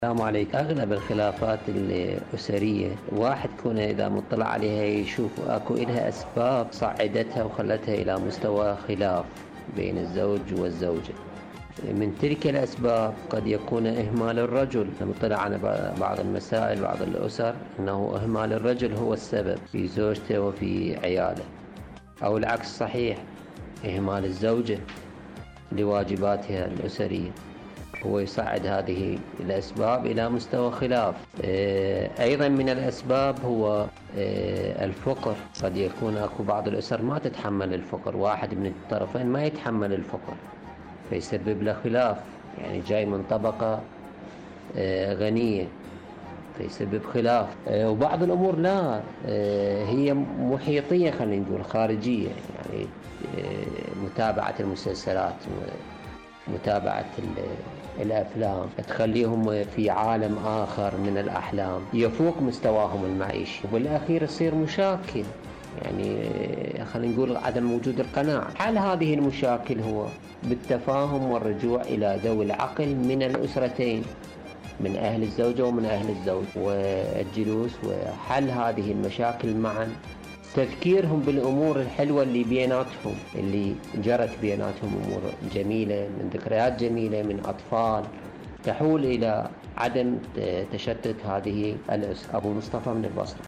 إذاعة طهران- معكم على الهواء